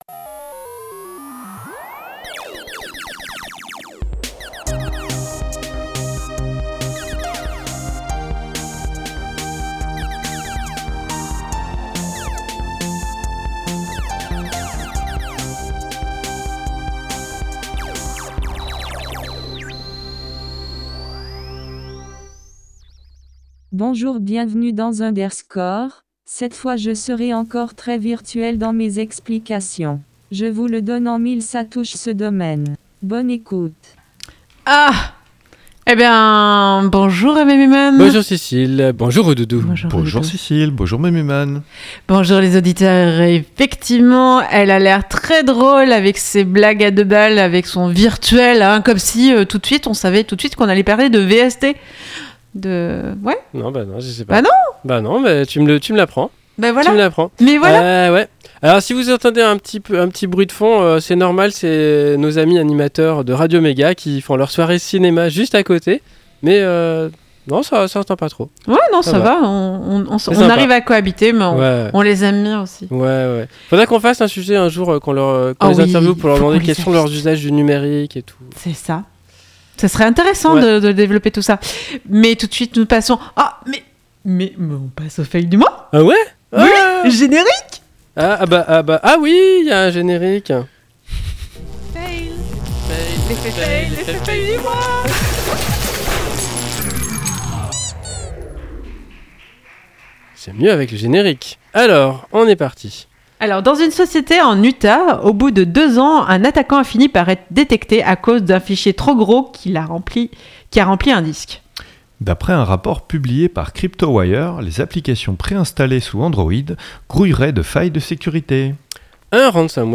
Le monde de la musique en virtuel De l'actu, une pause chiptune, un sujet, l'agenda, et astrologeek !